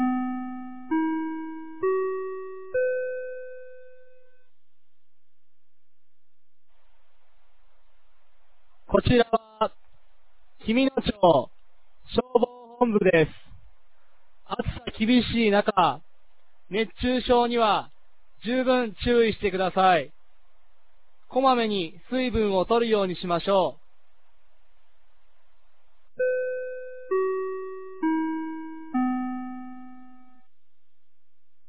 2025年07月26日 16時00分に、紀美野町より全地区へ放送がありました。